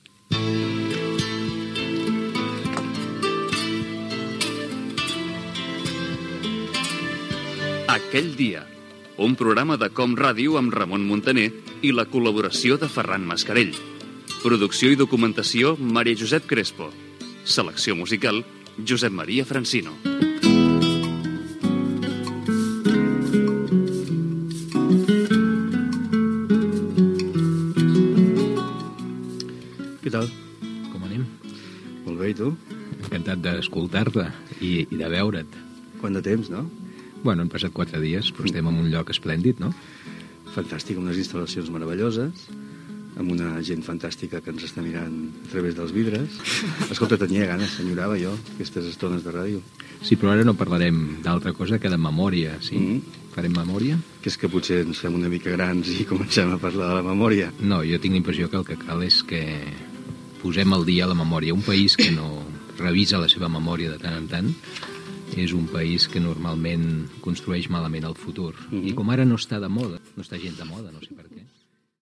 Indicatiu del programa, equip, presentació del primer programa
Entreteniment